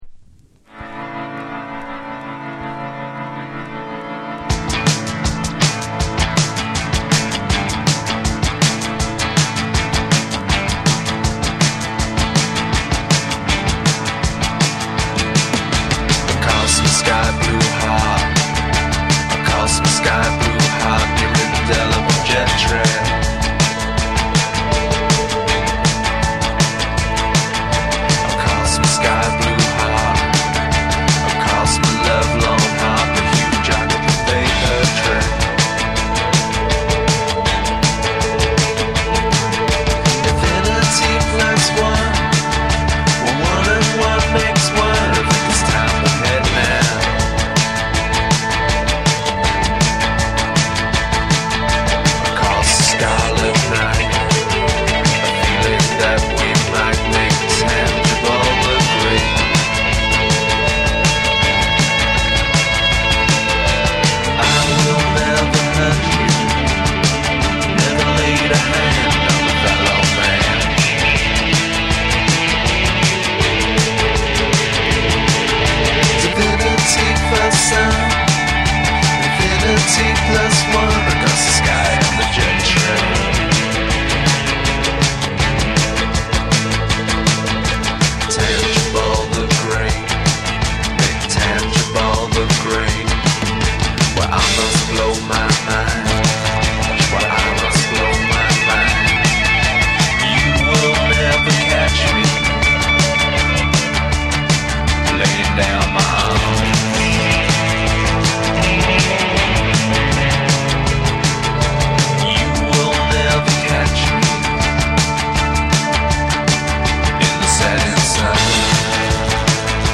TECHNO & HOUSE / NEW WAVE & ROCK